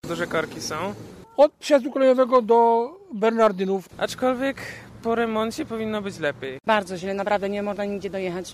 Posłuchajcie: Nazwa Plik Autor Kierowcy o remoncie ul. Wojska Polskiego w Piotrkowie audio (m4a) audio (oga) Oddanie pierwszego etapu Wojska Polskiego znacznie poprawiło komunikację.